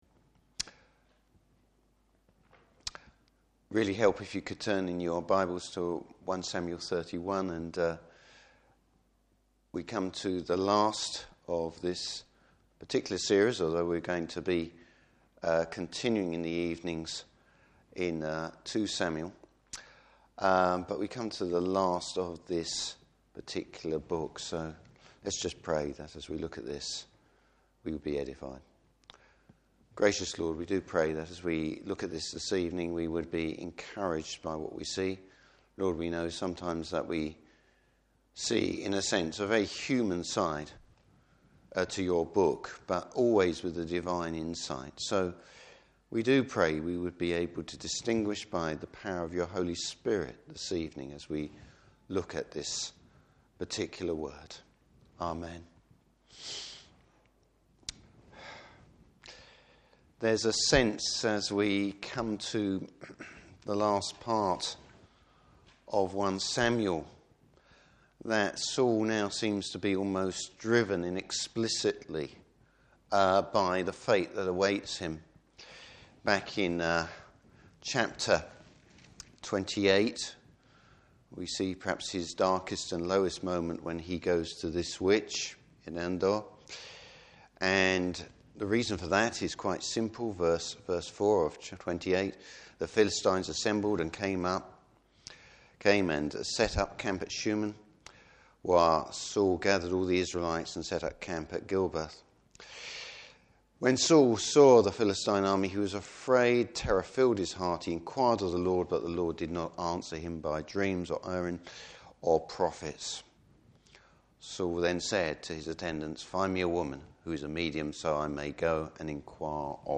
Service Type: Evening Service The final act in the life of Saul.